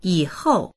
yǐhòu 以后 3 その後、今後
yi3hou4.mp3